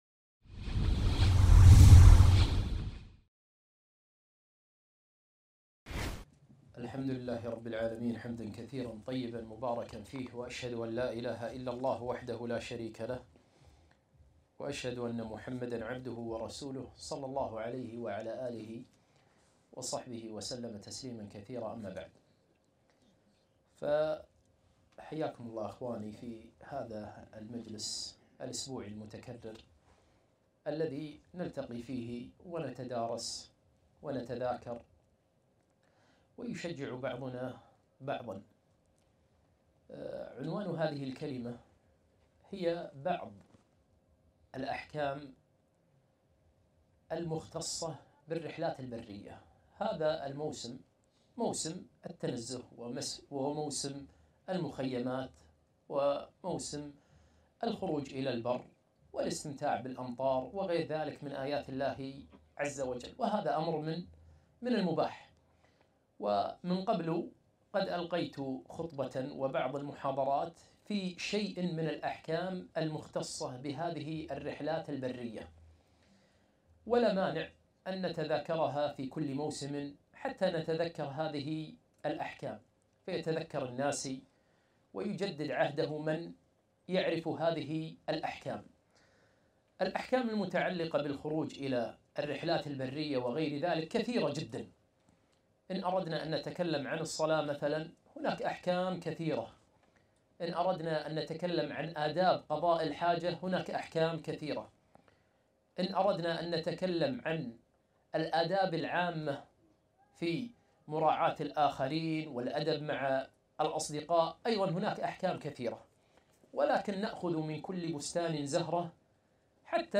محاضرة - من أحكام الرحلات البرية